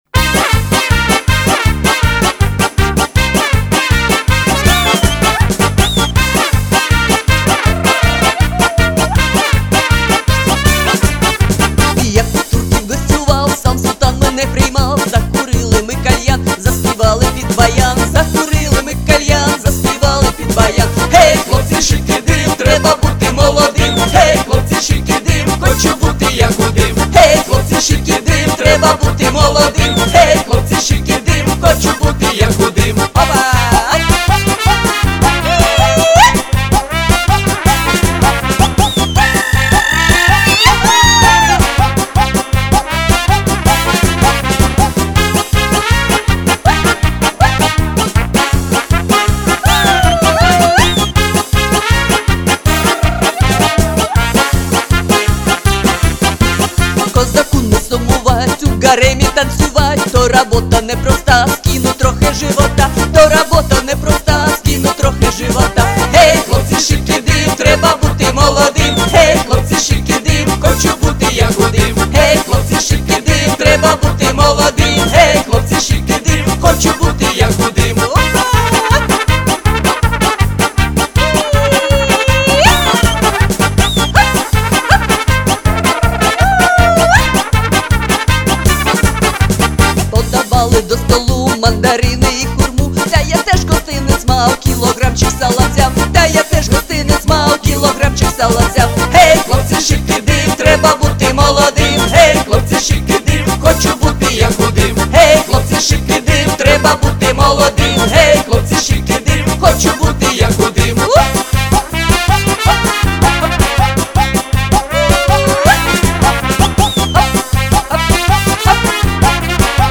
Молодцы- красиво и ритмично!! bravo_bigbuket_serdechkibuket